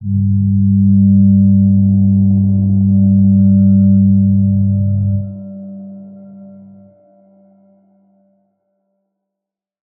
G_Crystal-G3-pp.wav